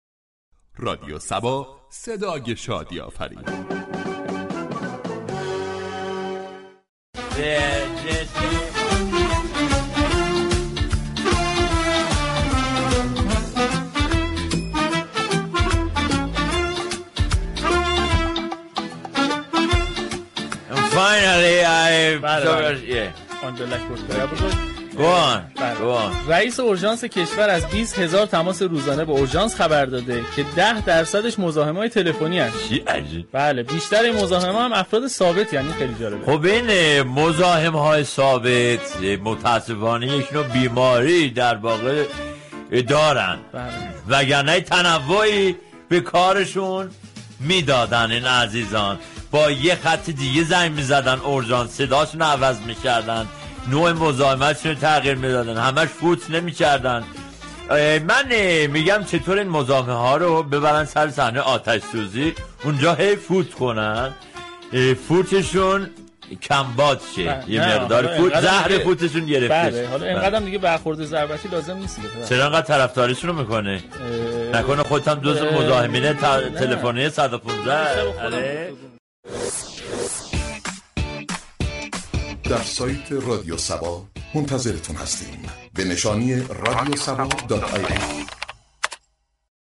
صبح صبا كه هر روز درساعت 07:00 صبح با پرداختن به موضوعات و اخبار روز جامعه ،لبخند و شادی را تقدیم مخاطبان می كند در بخش خبری با بیان طنز به خبر مزاحمت تلفنی برای اورژانس پرداخت.